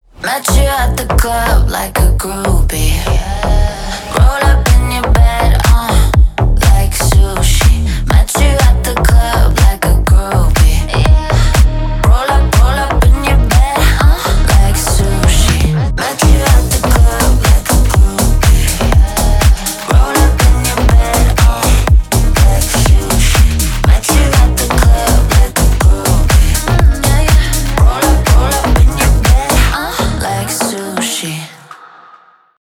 • Качество: 320 kbps, Stereo
Танцевальные
клубные
громкие